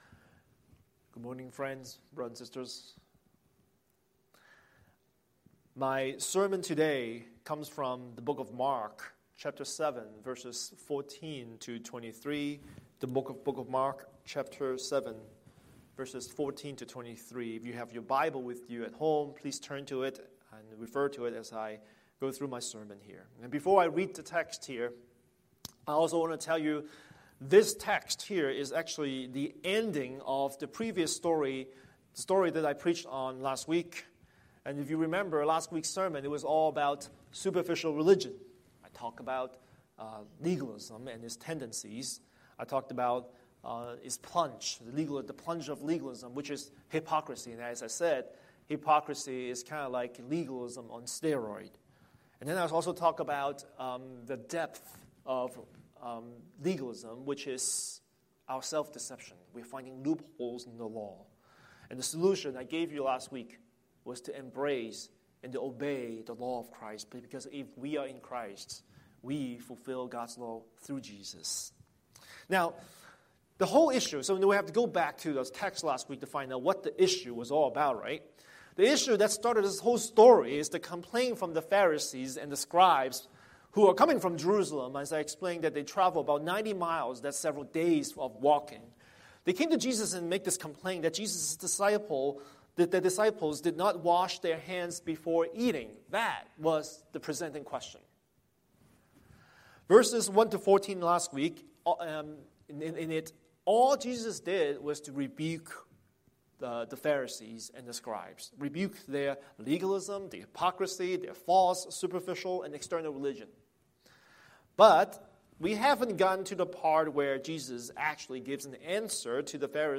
Scripture: Mark 7:14-23 Series: Sunday Sermon